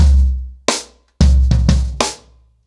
Wireless-90BPM.33.wav